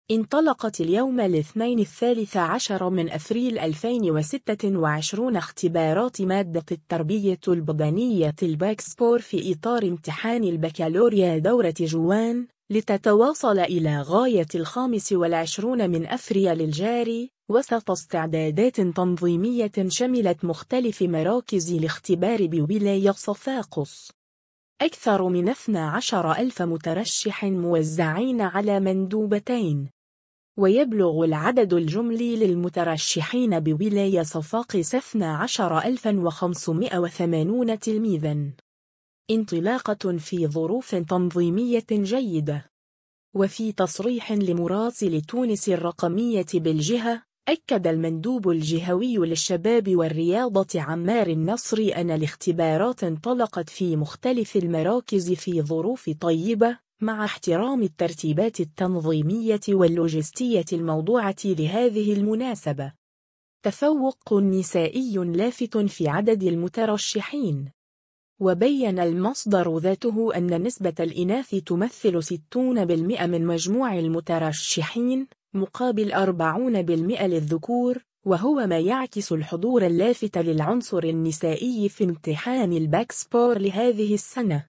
وفي تصريح لمراسل “تونس الرقمية” بالجهة، أكد المندوب الجهوي للشباب والرياضة عمار النصري أن الاختبارات انطلقت في مختلف المراكز في ظروف طيبة، مع احترام الترتيبات التنظيمية واللوجستية الموضوعة لهذه المناسبة.